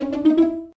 accent_4_notes.ogg